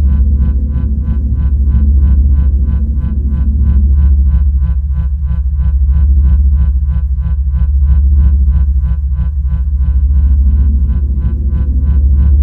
LightningTrain.ogg